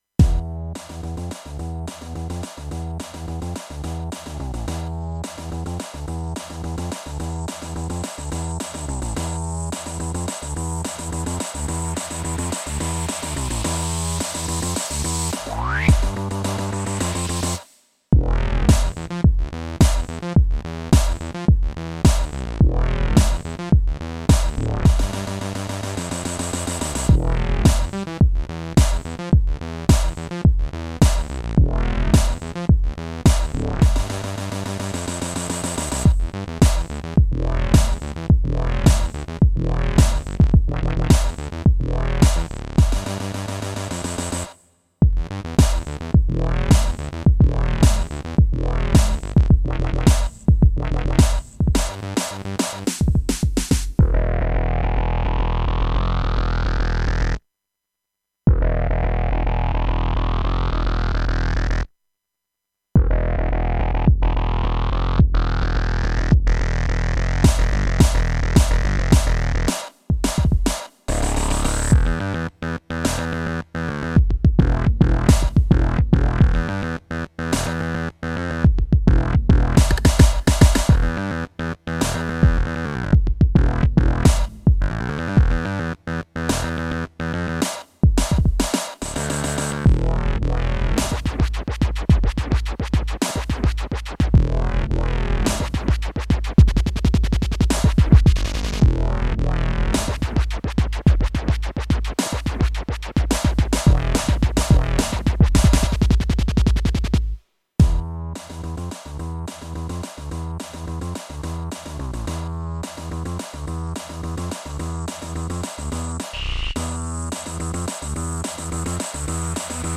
karaoke cover
Both covers lean into the strengths of each source.
Both played on my SC-8820.